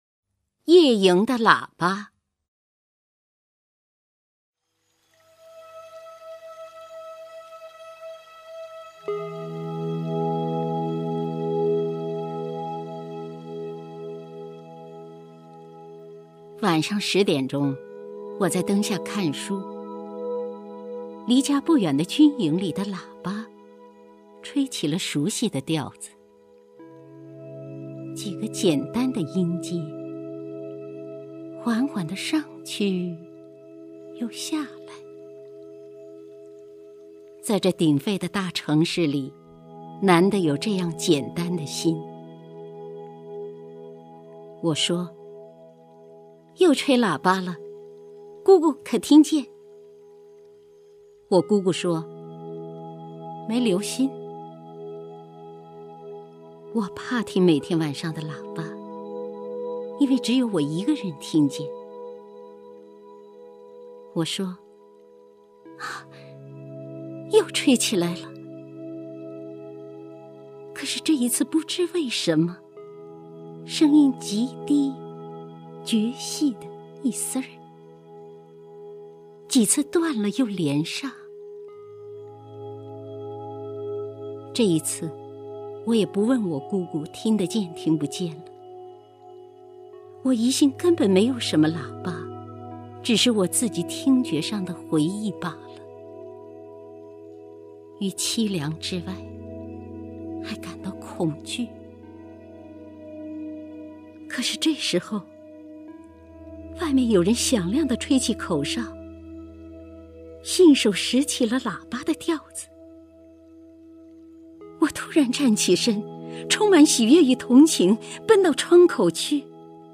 首页 视听 名家朗诵欣赏 姚锡娟
姚锡娟朗诵：《夜营的喇叭》(张爱玲)
YeYingDeLaBa_ZhangAiLing(YaoXiJuan).mp3